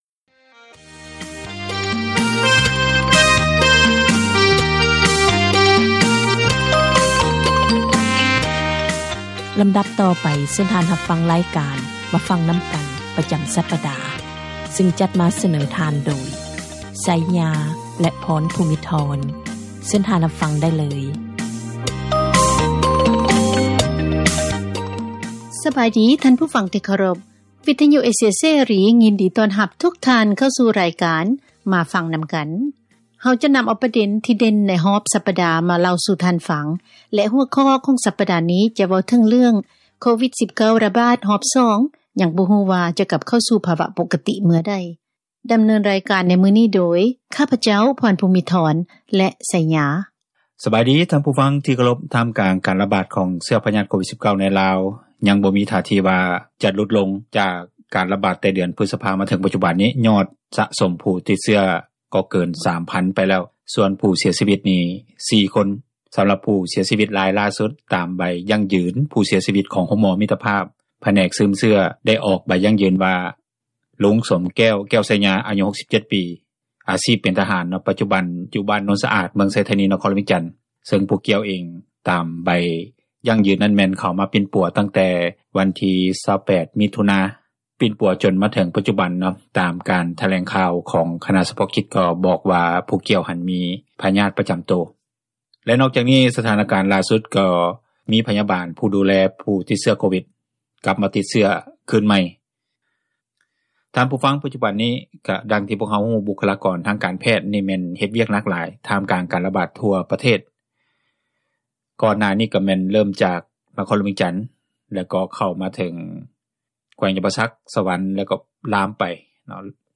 ການສົນທະນາ ໃນບັນຫາ ແລະ ຜົລກະທົບຕ່າງໆ ທີ່ເກີດຂຶ້ນ ຢູ່ປະເທດລາວ.